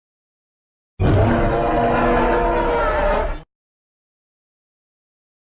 Allosaurus Roar